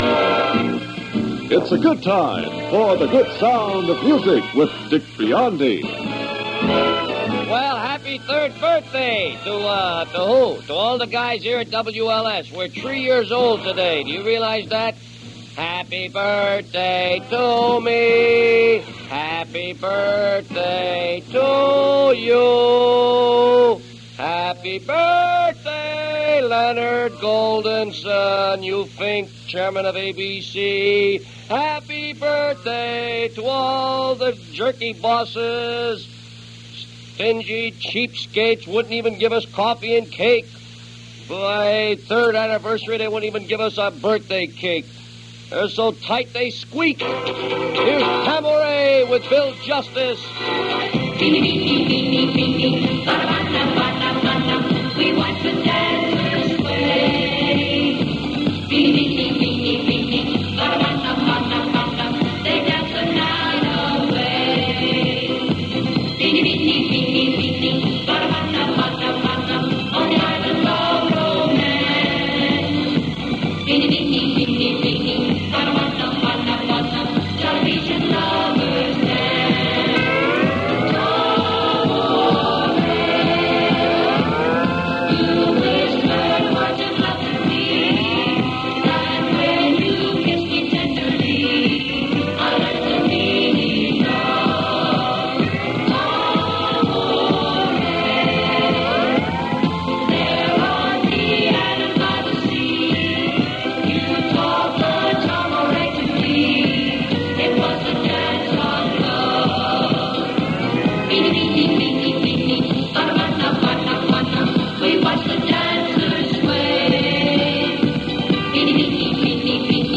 Personality Radio was everywhere in the 1960s.